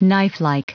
Prononciation du mot knifelike en anglais (fichier audio)
Prononciation du mot : knifelike